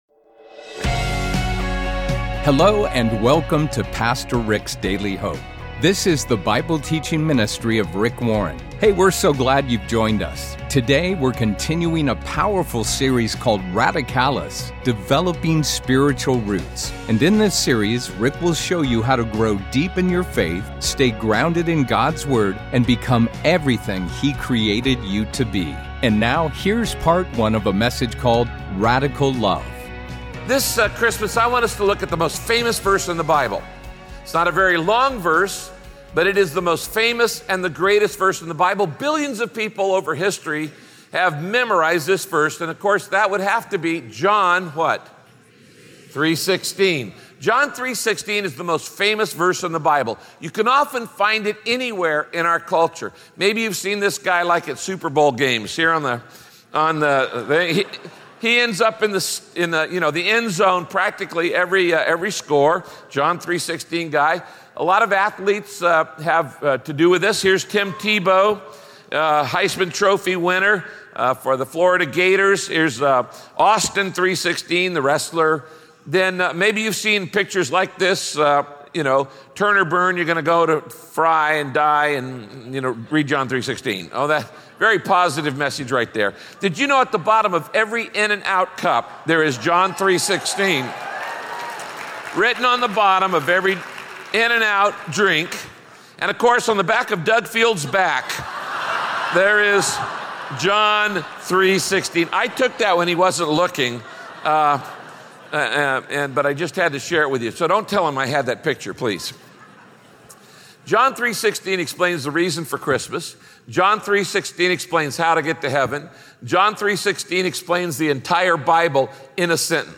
In this message, Pastor Rick teaches how God's love is intense, eternal, and everywhere!